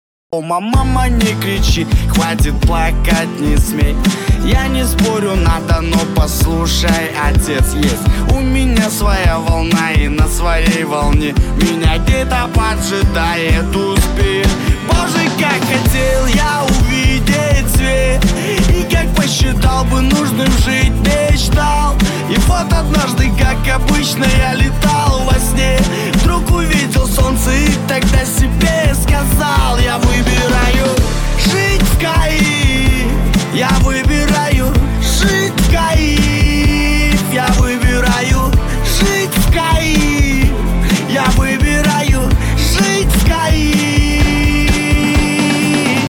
• Качество: 192, Stereo
позитивные
мотивирующие
вдохновляющие
воодушевляющие